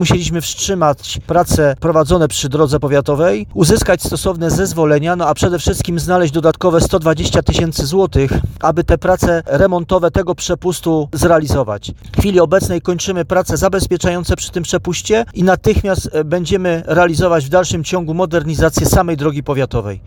Zarząd Powiatu Żywieckiego podjął decyzję o sfinansowaniu remontu przepustu, ale spowodowało to konieczność wstrzymania prac przy remoncie drogi, mówi wicestarosta Stanisław Kucharczyk.